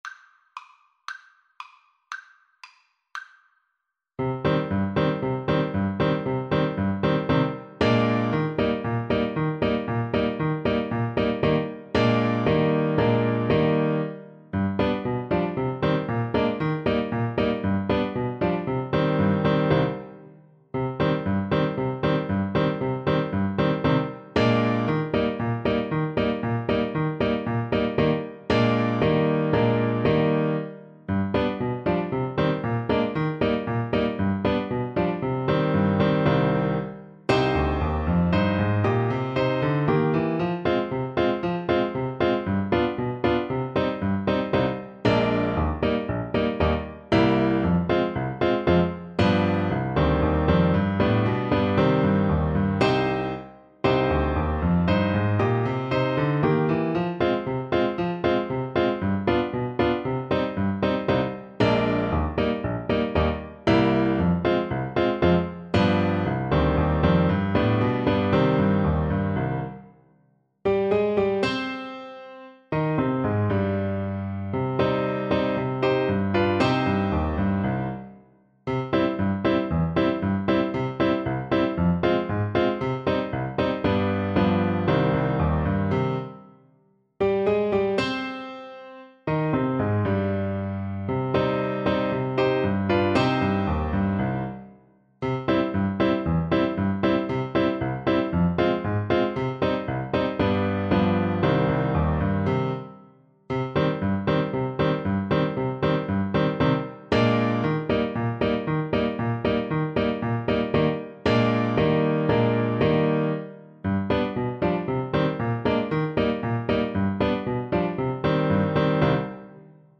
2/4 (View more 2/4 Music)
Allegro =c.116 (View more music marked Allegro)
Traditional (View more Traditional Saxophone Music)